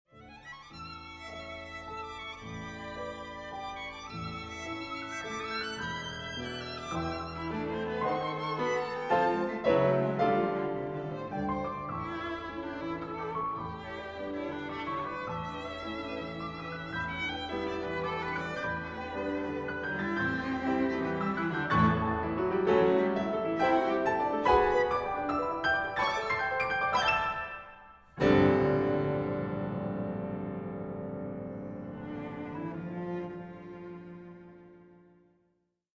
Piano Quintet